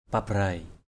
/pa-braɪ/ 1.